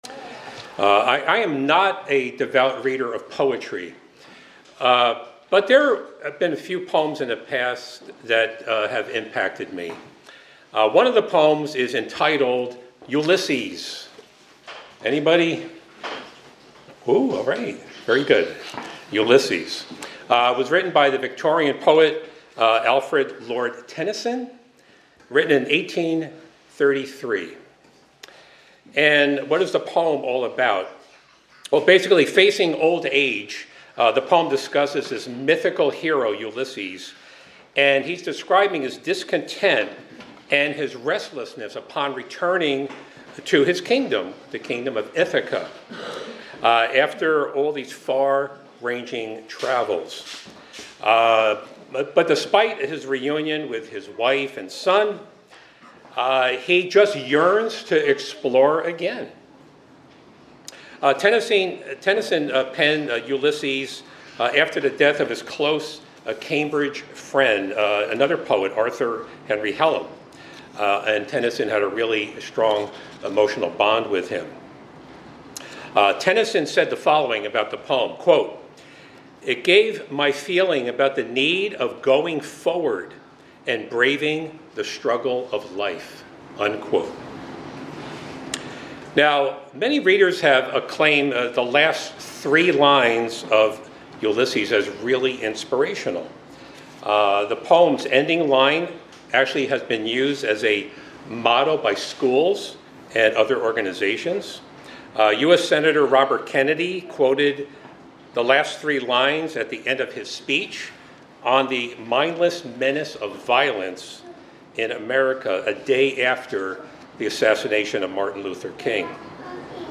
This sermon, Not to Yield, calls believers to steadfast faith and perseverance despite trials, loneliness, opposition, or age.
Given in Hartford, CT